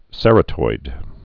(sĕrə-toid)